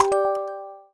Special & Powerup (5).wav